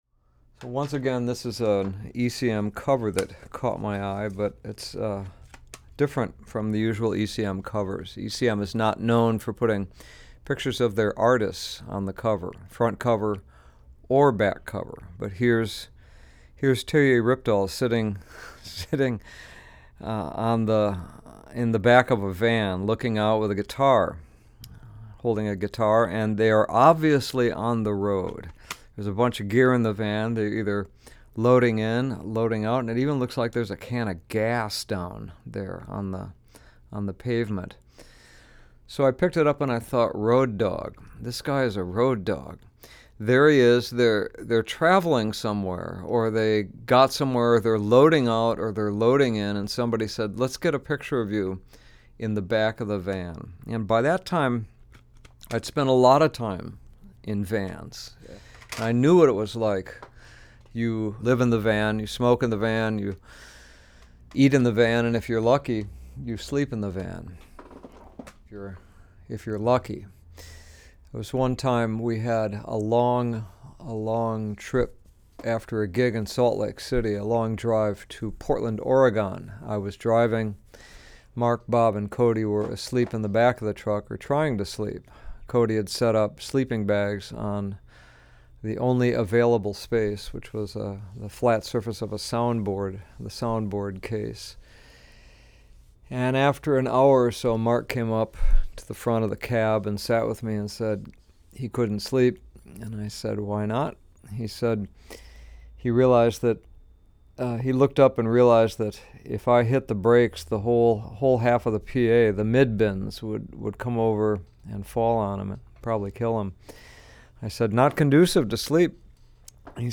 STEVE TIBBETTS TALKING